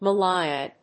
音節Ma・lay・a 発音記号・読み方
/məléɪə(米国英語)/